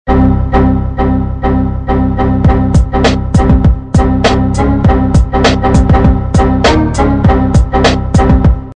دانلود صدای خطای ویندوز 2 از ساعد نیوز با لینک مستقیم و کیفیت بالا
جلوه های صوتی